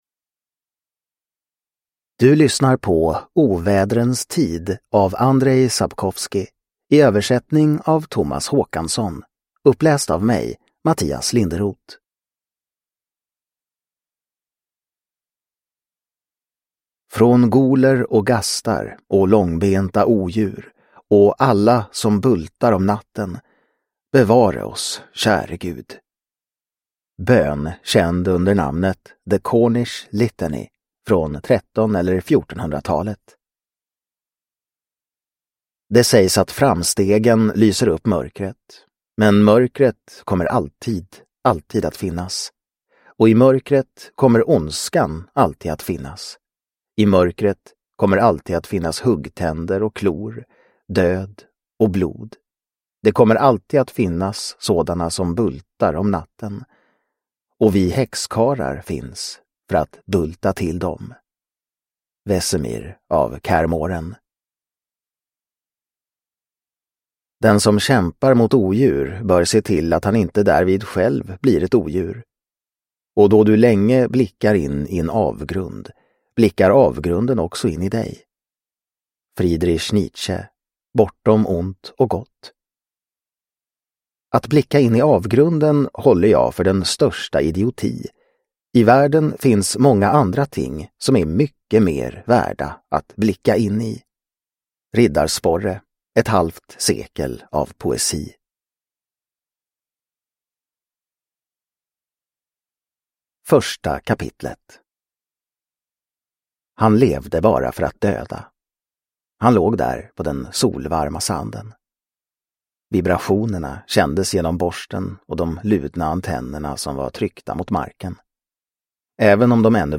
Ovädrens tid – Ljudbok – Laddas ner